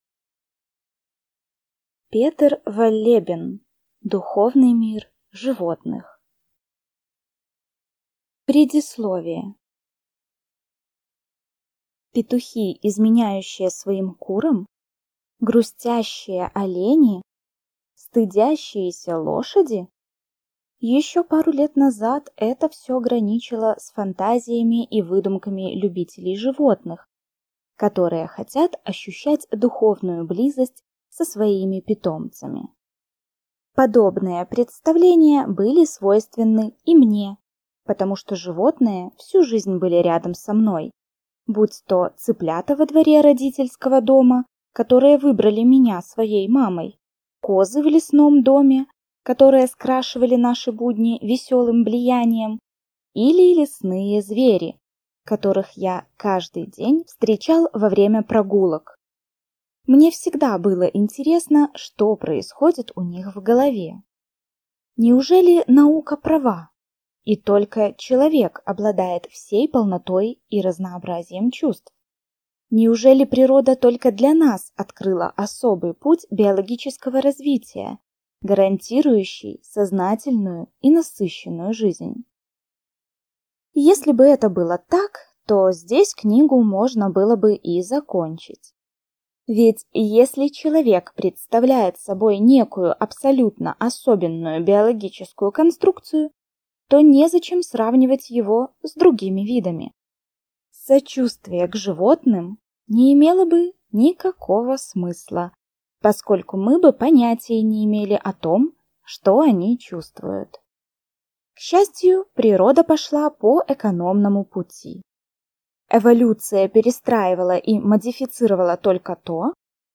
Аудиокнига Духовный мир животных | Библиотека аудиокниг